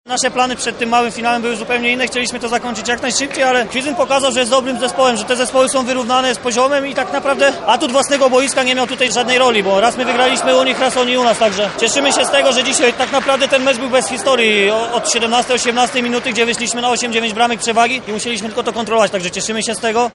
Mówi zawodnik gospodarzy